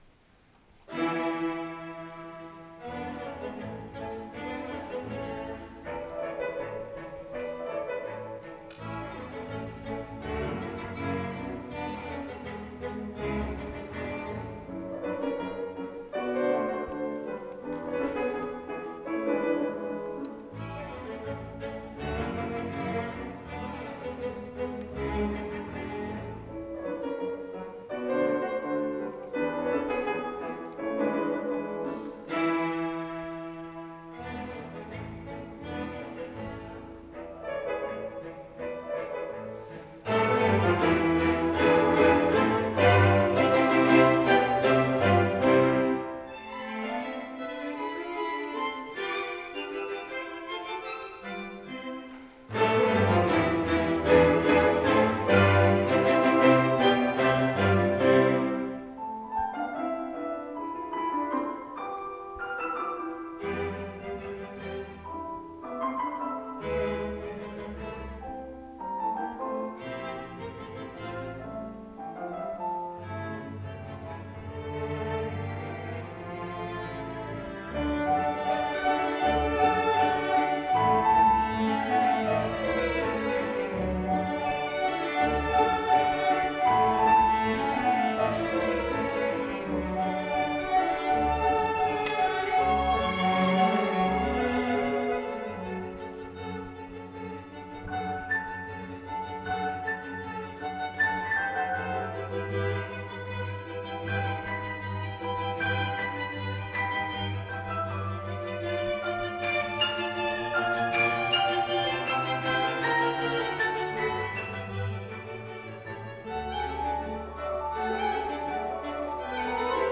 Selected live recordings